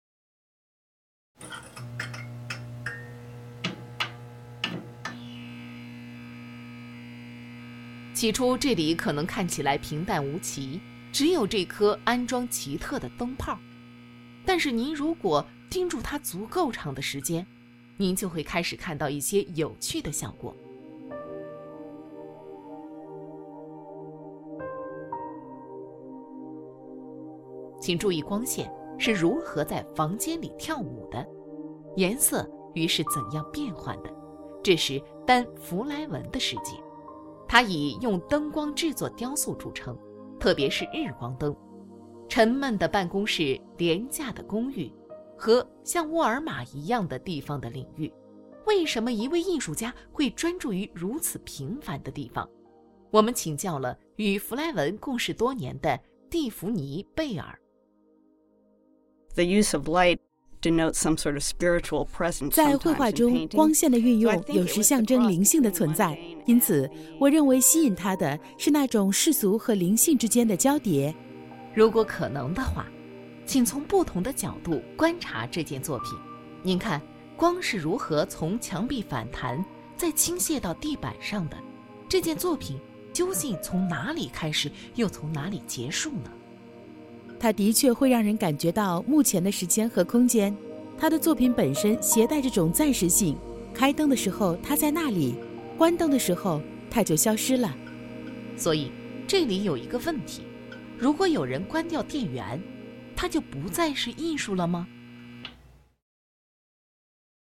Audio Stories